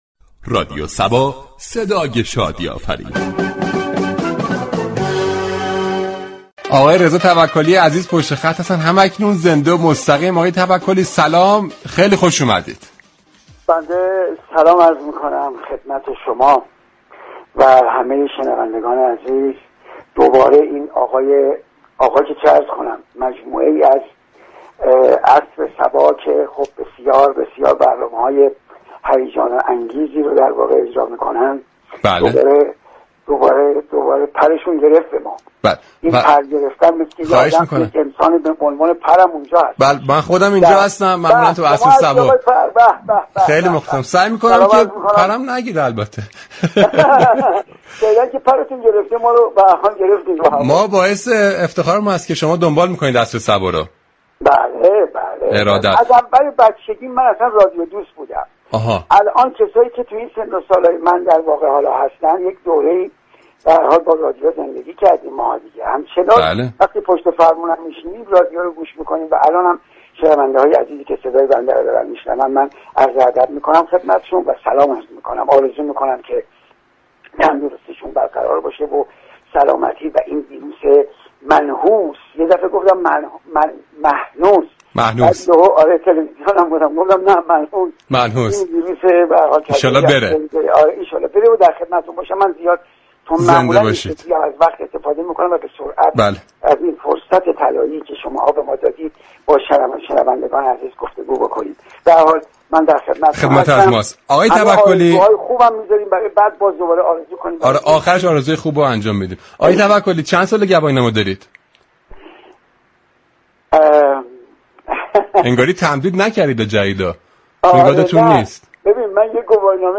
رضا توكلی بازیگر خوب كشورمان در گفتگو شاد وصمیمی با برنامه عصر صبا از علاقه خودبه رادیو از كودكی گفت .
به گزارش روابط عمومی رادیو صبا، برنامه زنده عصر صبا روز سه شنبه 11آبان با موضوع قوانین راهنمایی و رانندگی راهی آنتن شد و درباره این موضوع با رضا توكلی بازیگر خوب كشورمان گفتگو صمیمی و شادی داشت .